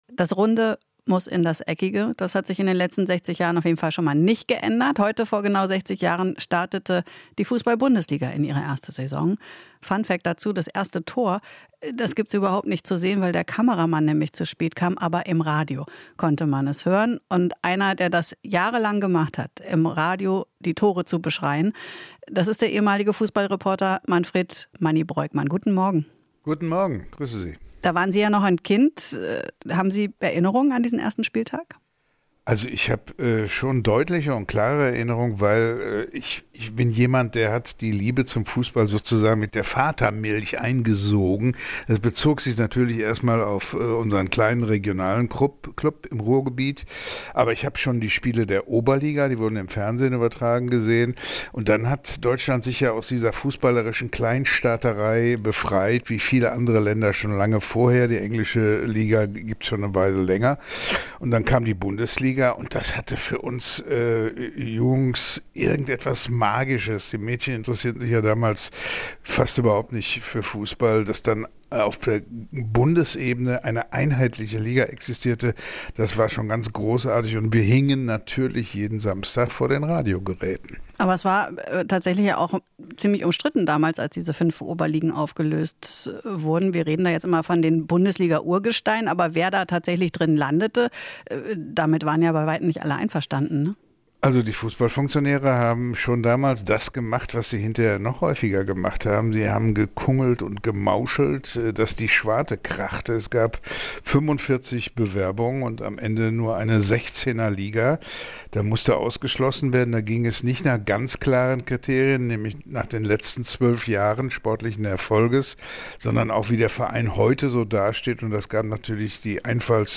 Interview - Reporterlegende Breuckmann: "Start der Bundesliga hatte etwas Magisches"